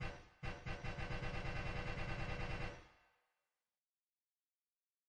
描述：使用来自freesound.Medium距离的声音重新创建光环needler
Tag: 科幻 卤素 刺针